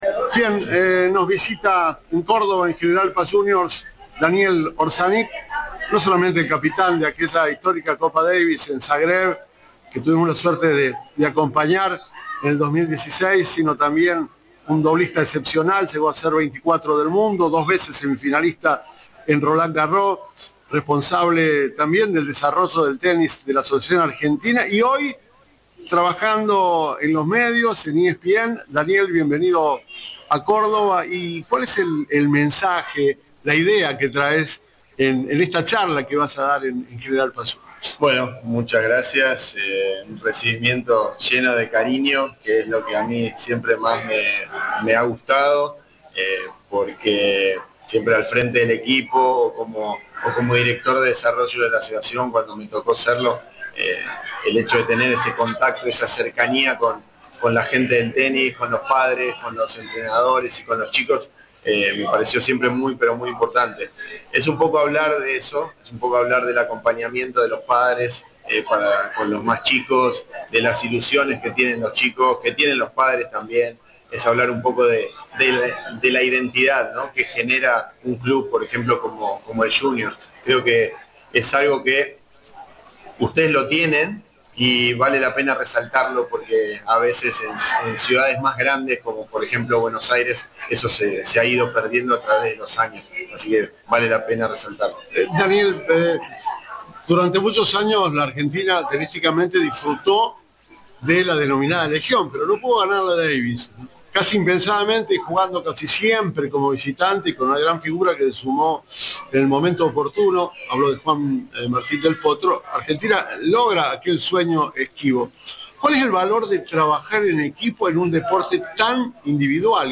"El hecho de tener ese contacto, esa cercanía con la gente del tenis, con los padres, con los entrenadores y con los chicos, me pareció siempre muy importante", afirmó Orsanic en diálogo con Cadena 3.
Entrevista